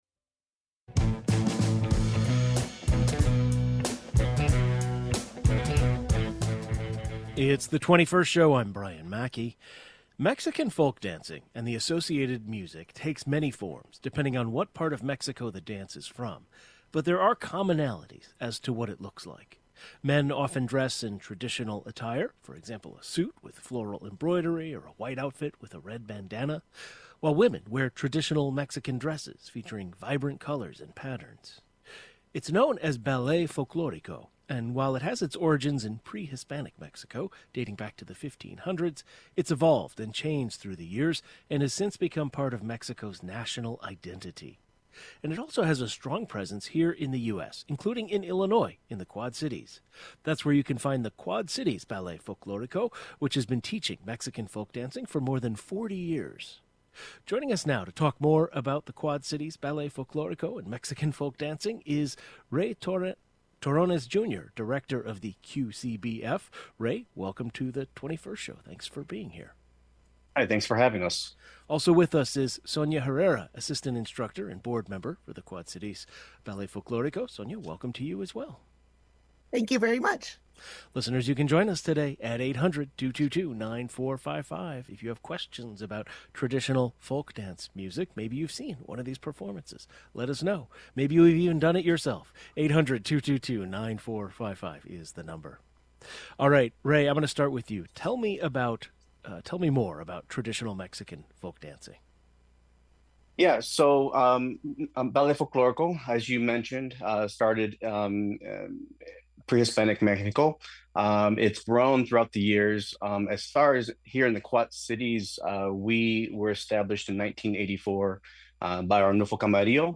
The sights and sounds of traditional Mexican folk dancing. We’ll talk with members of Quad Cities Ballet Folklorico, which has been teaching young people this cultural dance for more than 40 years.